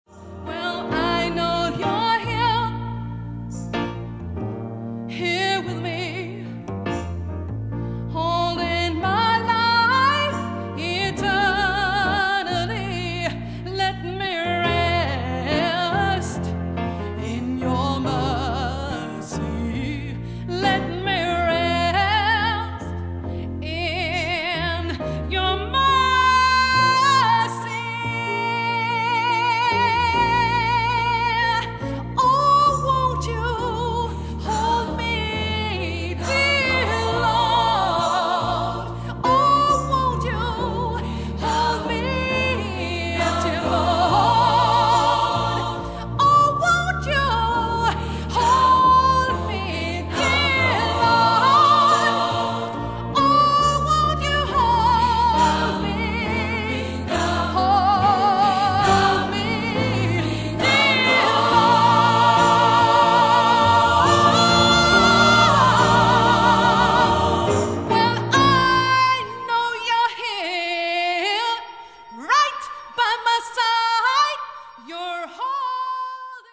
gospelalbum, inspelat live i Högalidskyrkan i Sthlm.